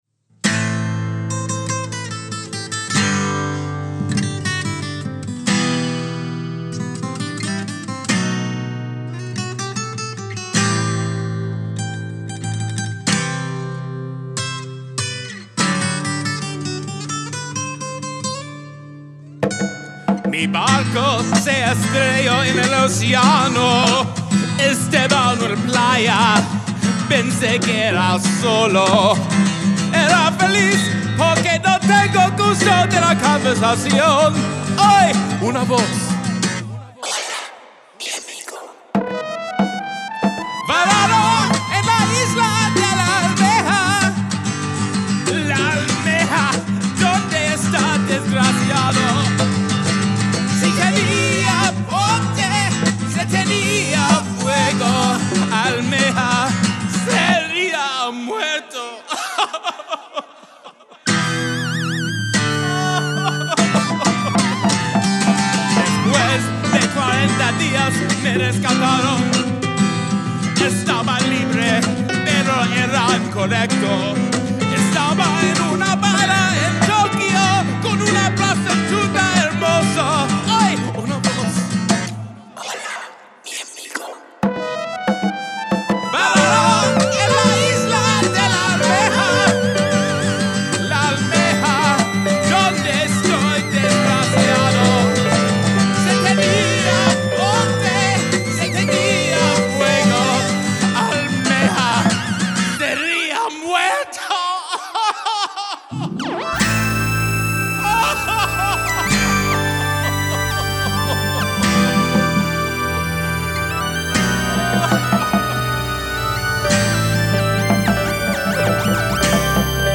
Song must include whispering